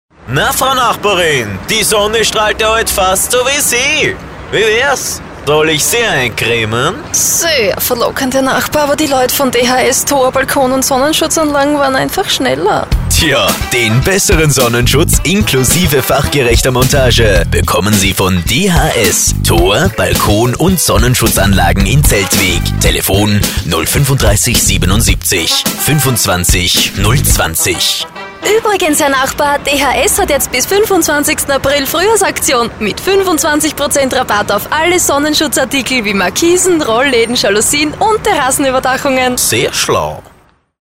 deutschsprachiger Sprecher.
Sprechprobe: Industrie (Muttersprache):
german voice over artist